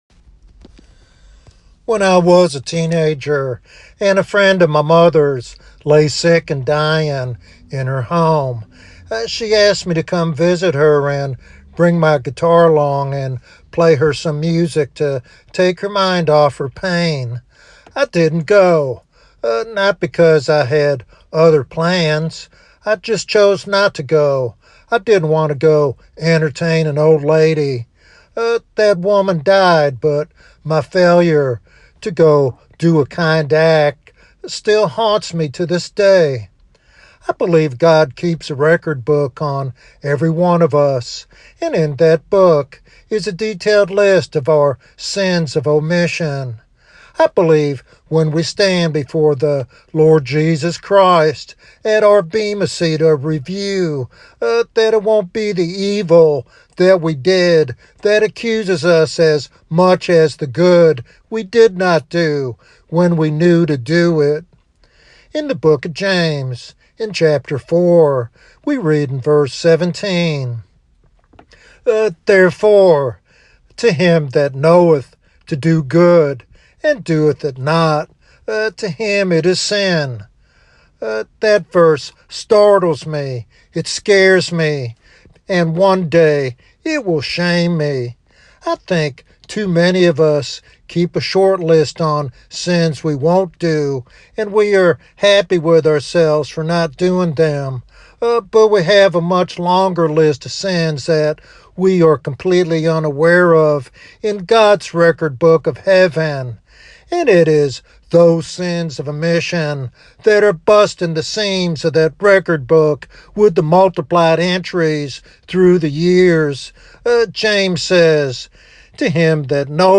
This sermon calls Christians to live with intentionality, embracing prayer, witness, and acts of kindness as vital expressions of faith.